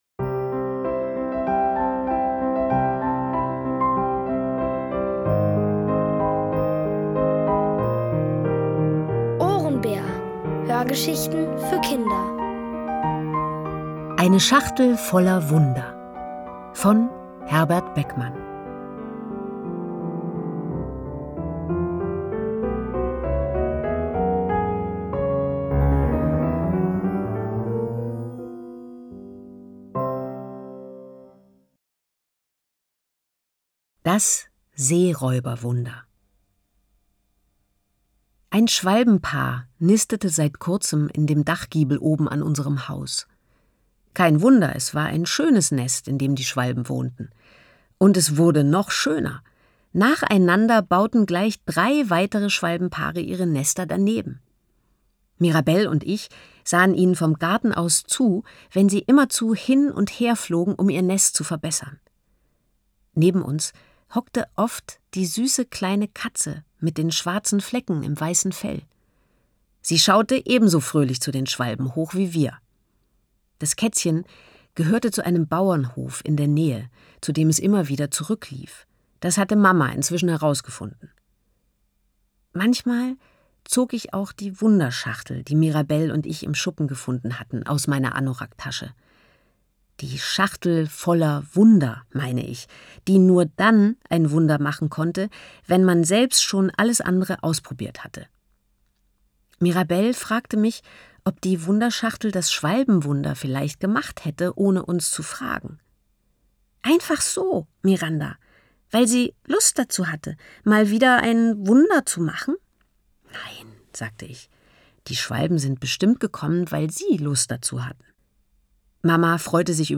Von Autoren extra für die Reihe geschrieben und von bekannten Schauspielern gelesen.
Es liest: Nina Hoss.